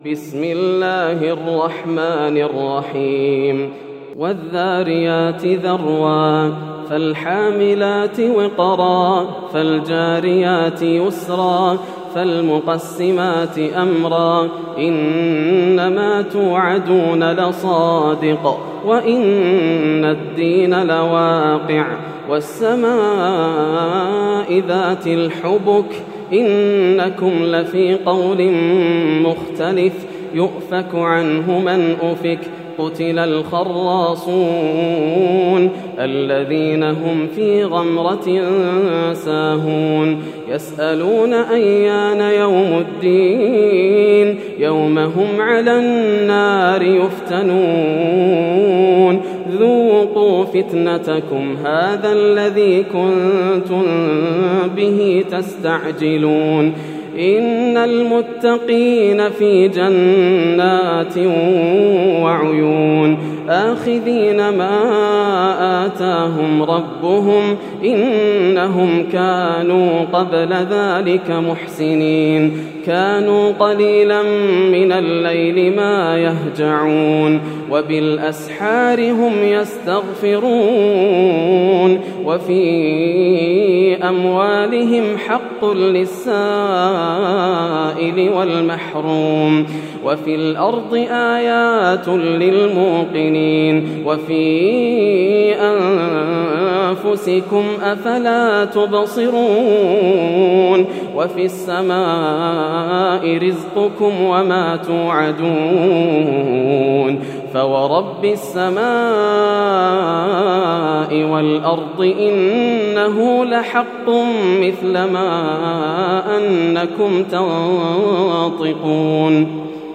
سورة الذاريات > السور المكتملة > رمضان 1431هـ > التراويح - تلاوات ياسر الدوسري